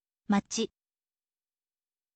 machi